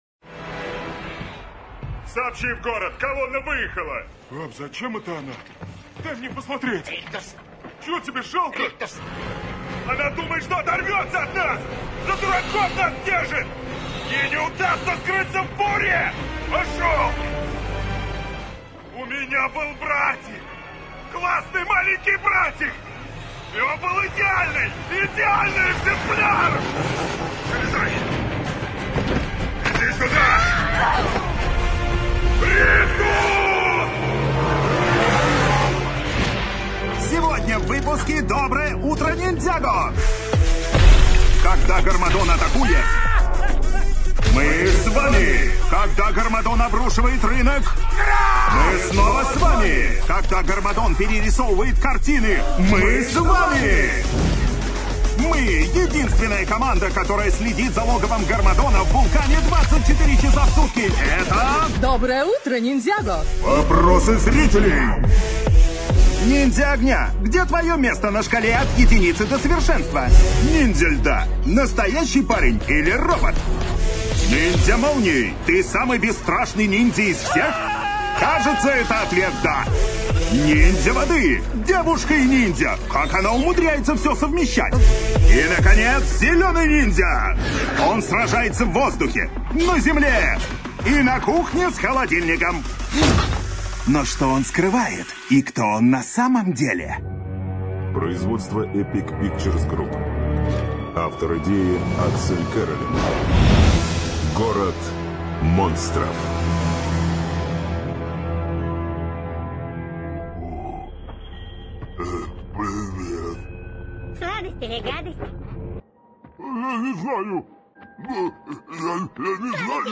Тракт: Zoom H4n, шумоизолированное помещение.
Демо-запись №3 Скачать